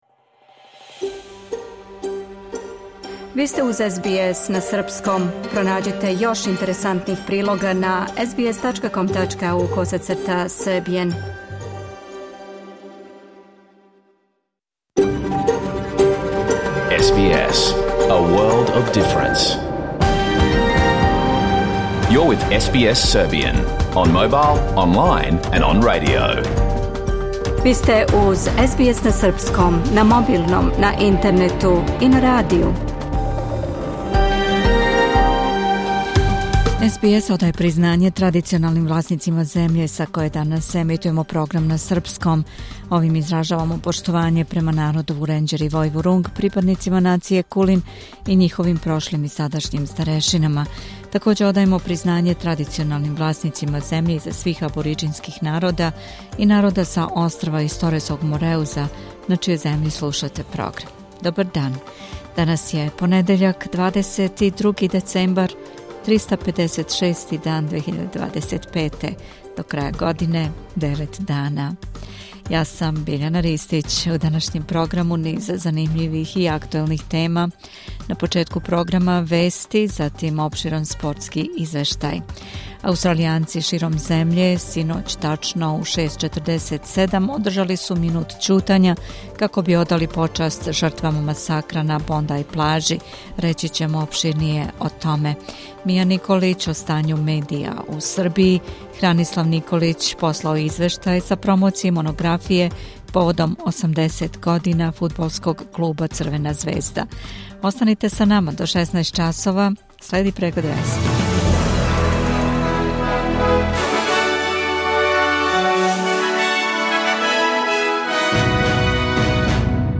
Програм емитован уживо 22. децембра 2025. године